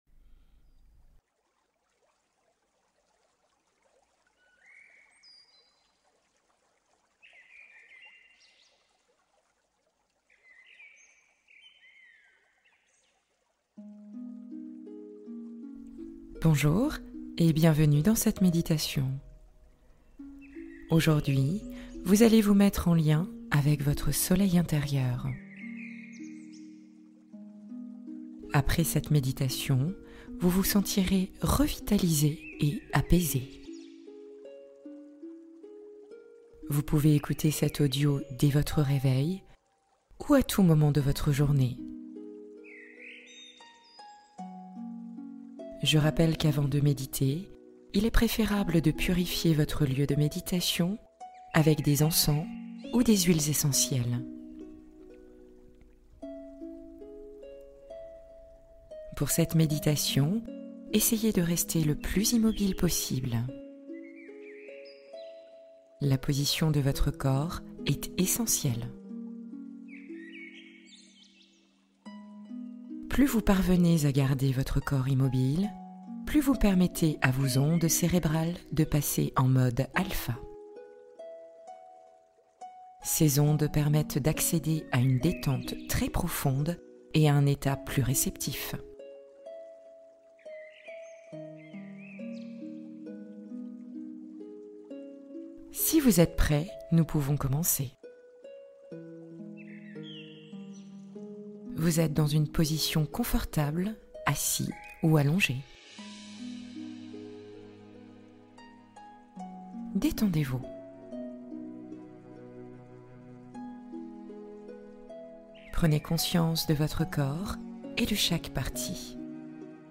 Éveiller son soleil intérieur : méditation du matin énergisante